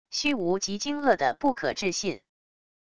虚无极惊愕的不可置信wav音频